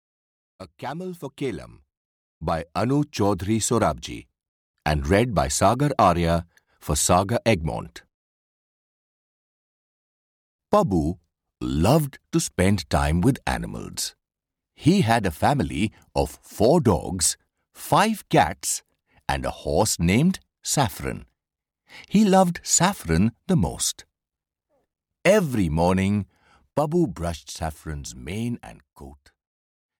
A Camel for Kelam (EN) audiokniha
Ukázka z knihy